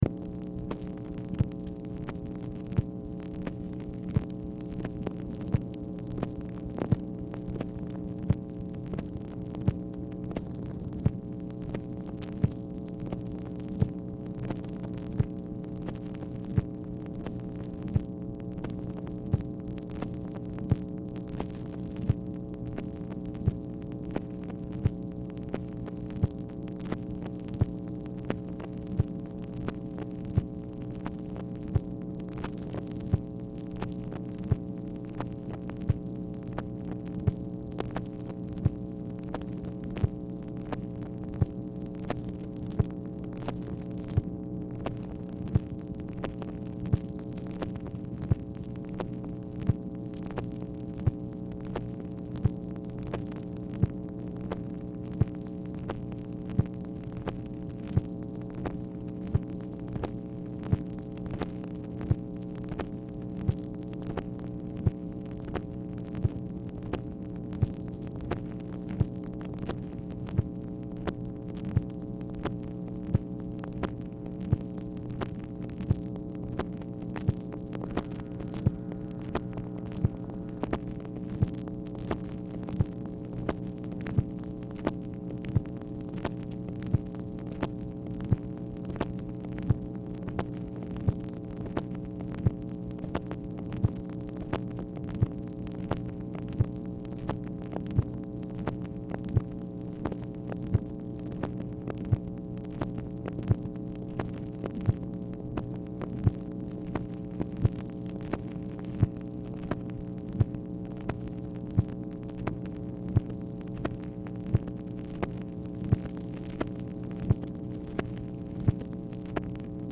Telephone conversation # 7588, sound recording, MACHINE NOISE, 5/5/1965, time unknown | Discover LBJ
Format Dictation belt